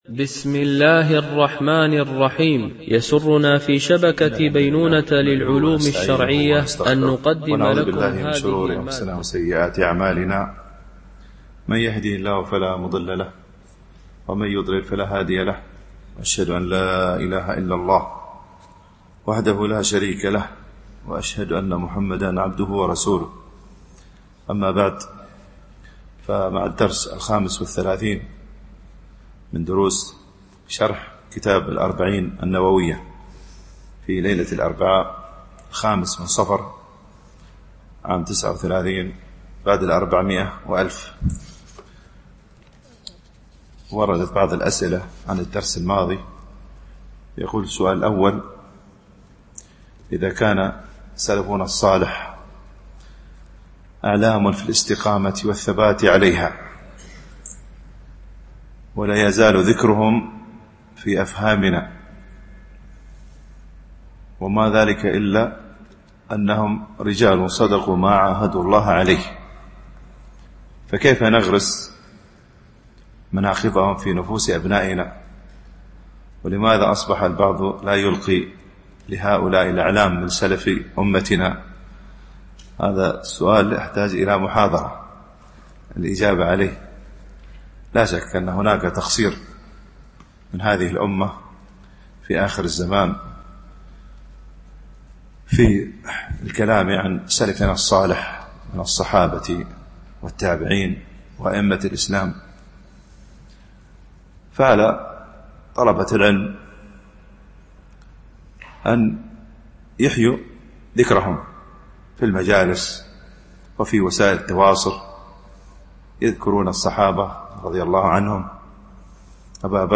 شرح الأربعين النووية ـ الدرس 35 (الحديث 22)